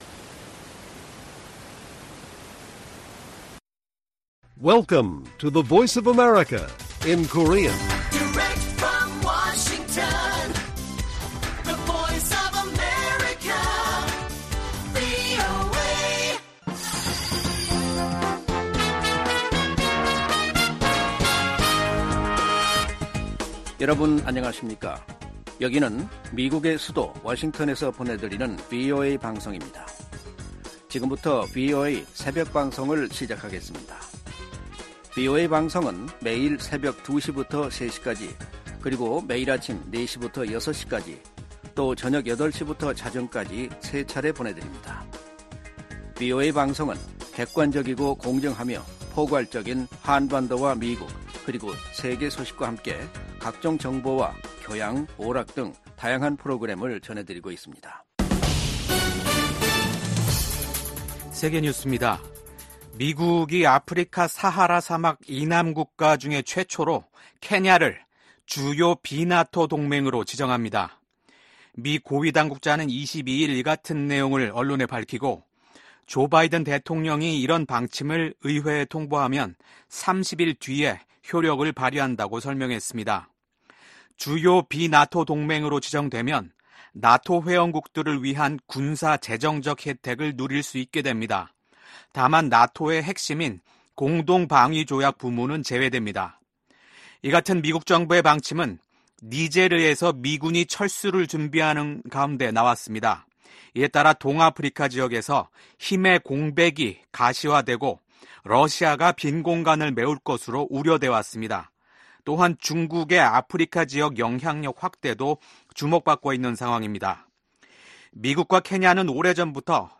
VOA 한국어 '출발 뉴스 쇼', 2024년 5월 24일 방송입니다. 토니 블링컨 국무장관은, 미국이 한국, 일본과 전례 없는 방식으로 공조하고 있다고 하원 청문회에서 증언했습니다. 북한이 러시아에 군수품을 제공하면서 러시아가 무기 생산을 확대할 기회를 마련해줬으며, 러시아는 그 대가로 북한에 기술을 제공할 수 있다고 미국 합참의장이 평가했습니다. 미국 와이오밍주가 북한과 연계된 회사 3곳에 폐쇄 조치를 내렸습니다.